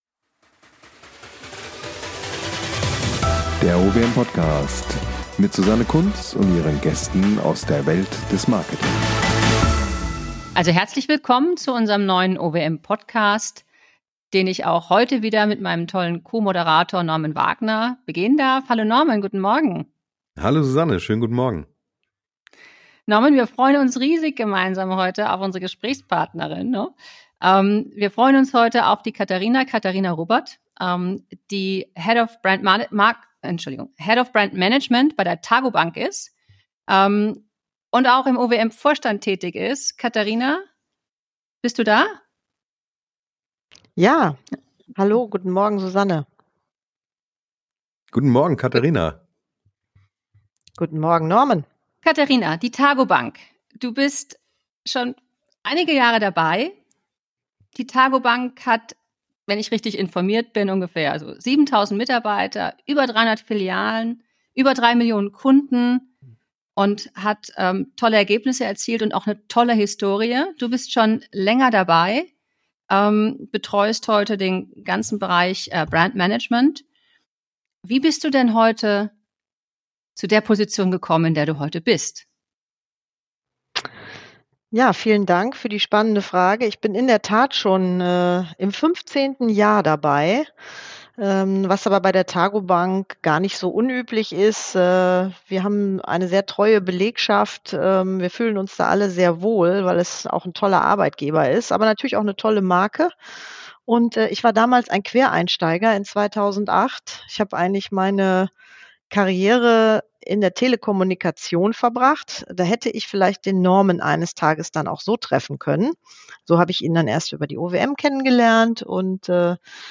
im Gespräch mit der OWM ~ Der OWM Podcast